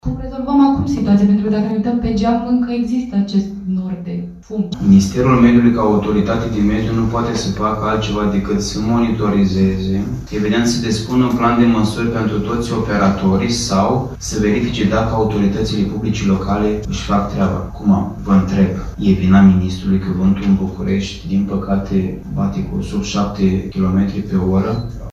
Valorile sunt depășite și la această oră în capitală, i-au atras atenția Ministrului jurnaliștii prezenți la conferința de presă:
02mar-12-Alexe-nu-pot-controla-viteza-vantului-1.mp3